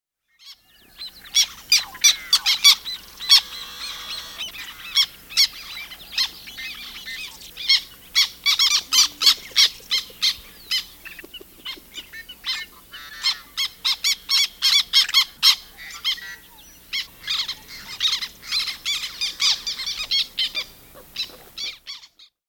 Genre: Chlidonias Espèce: niger
Nom anglais: Black Tern Date: 31 mai 2010
Endroit: Ile Bizard (Québec)
chant196.mp3